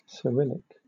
The Cyrillic script (/sɪˈrɪlɪk/
, /sə-/ sih-RILL-ik, sə-)[5][6] is a writing system used for various languages across Eurasia.